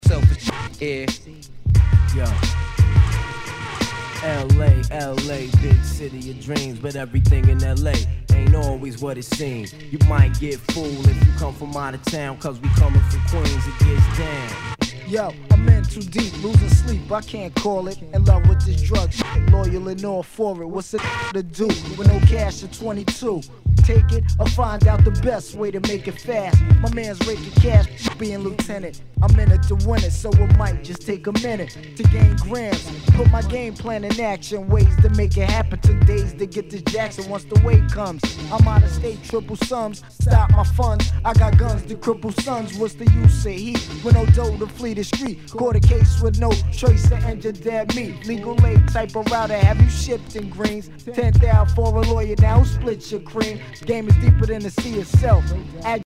当前位置 > 首页 >音乐 >唱片 >说唱，嘻哈
HOUSE/TECHNO/ELECTRO